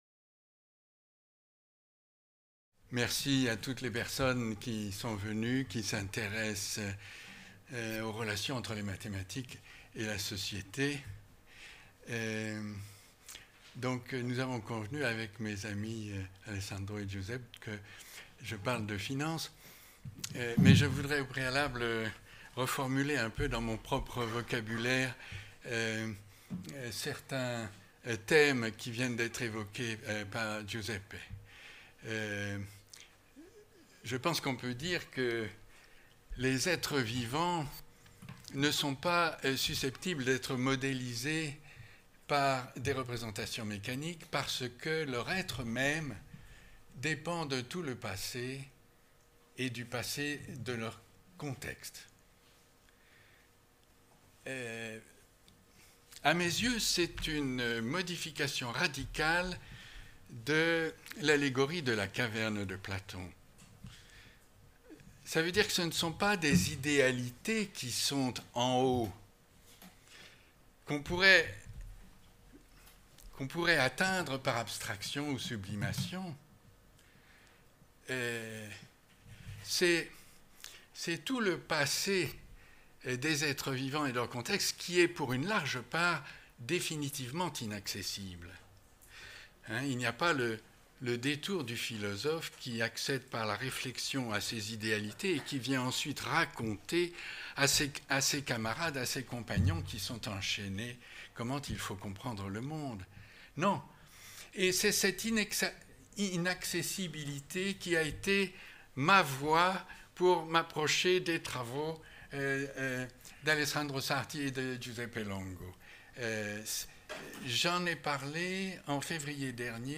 Exposé à l’Ecole Normale Supérieure le 14 décembre 2017 dans le cadre de la rencontre: